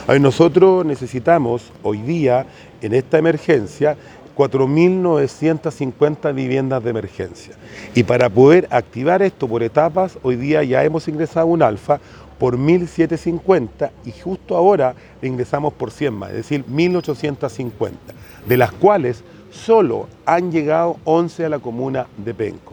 En el caso de Penco, su alcalde, Rodrigo Vera, dijo que se requieren casi cinco mil, 1.700 de ellas con celeridad.